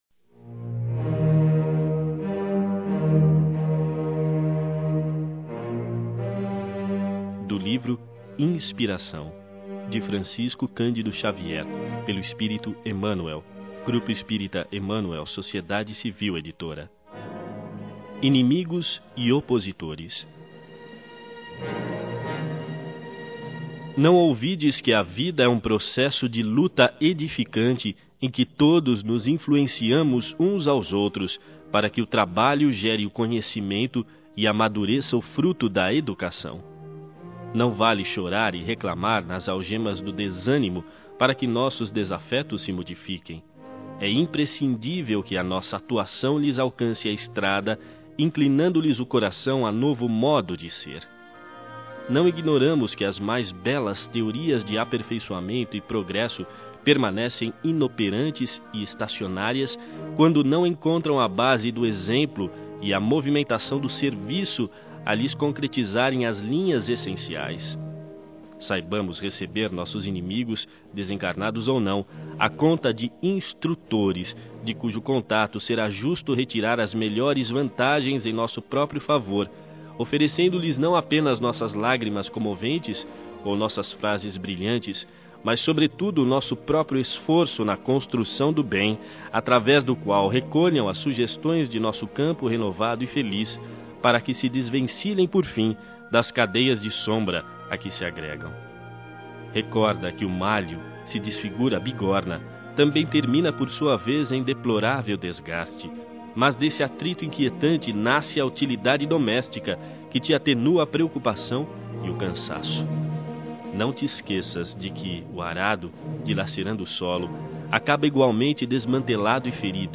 Mensagem: Inimigos e OpositoresLivro: Inspiração de LuzSeu navegador não suporta áudio.